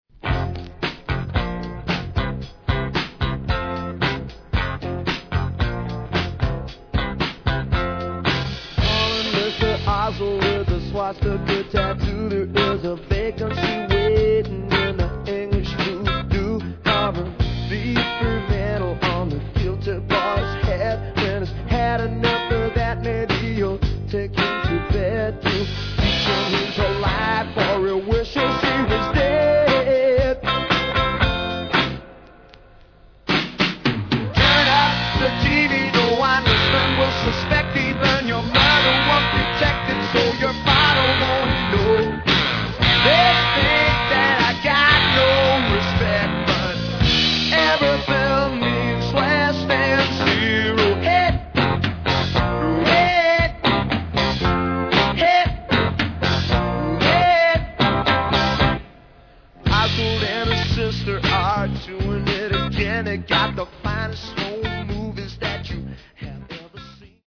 Category: Modern Rock